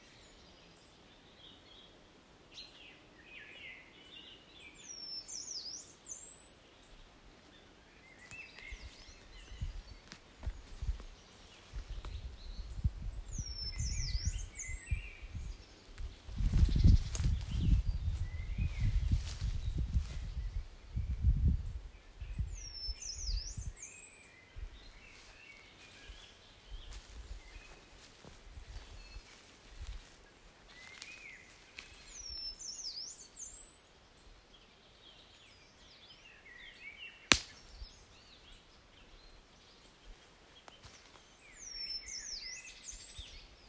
Outoa (edit: taigauunilintu)
Merikarvialaisessa merenrantapusikossa outo lauleskelija 24.6.2018. Kähmyili piilossa ison koivun latvuksessa yli 10 m korkeudessa ja veteli vajaan tunnin ajan säettä, josta oheisessa linkissä näyte (alkaa jostain viiden sekunnin kohdalta.
Laji sitten löytyi kun nettiä jaksoi kaivella: taigauunilintu.
... ja taustalla laulaa mustarastas, ei kuhankeittäjä